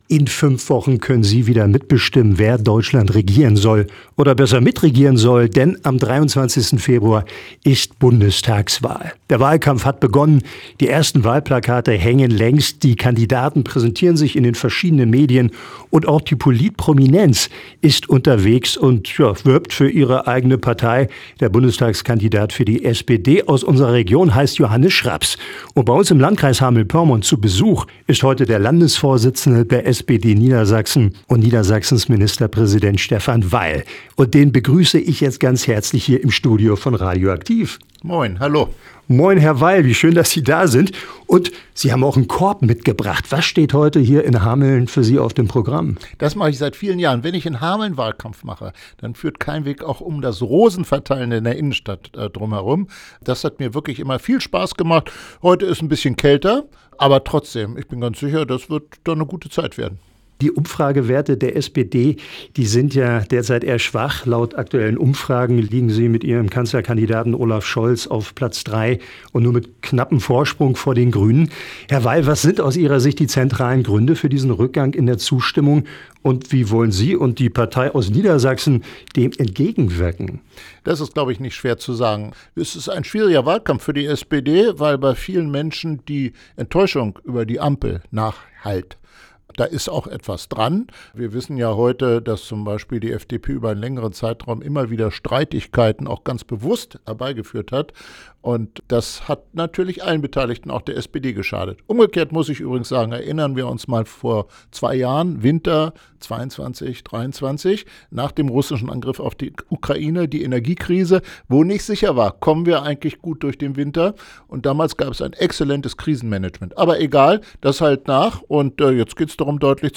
Hameln: Ministerpräsident Stephan Weil zu Gast bei radio aktiv – radio aktiv